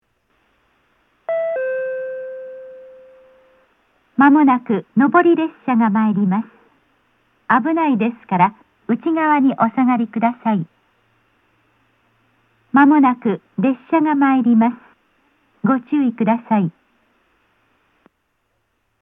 （女性）
接近放送
上り列車の接近放送です。線内標準のタイプでした。